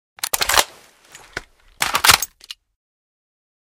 MP7 Reanimation / gamedata / sounds / weapons / librarian_mp7 / reload.ogg
reload.ogg